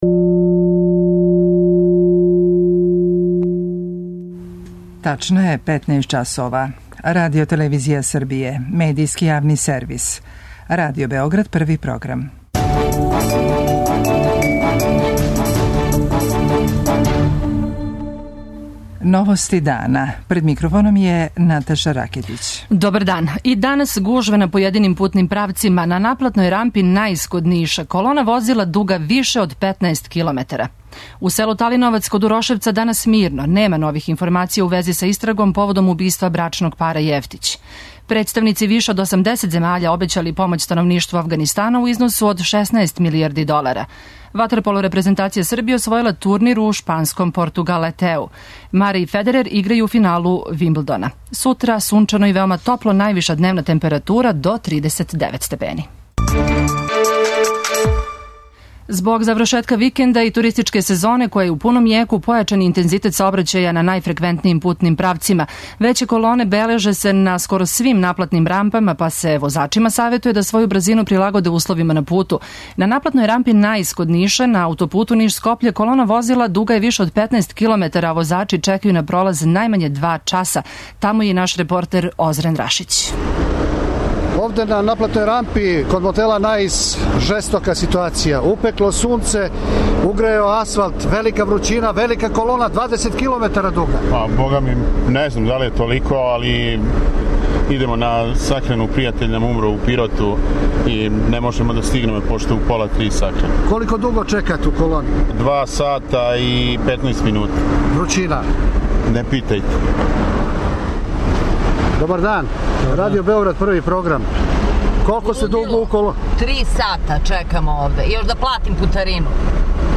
На путевима у Србији саобраћај је појачаног интензитета, а посебно на ауто-путевима који из правца Мађарске и Хрватске воде ка Бугарској и Македонији, као и на магистралном путу ка Црној Гори, саопштио је Ауто-мото савез Србије. Наши репортери налазе се на прелазу Хоргош и наплатној рампи НАИС, на аутопуту код Ниша.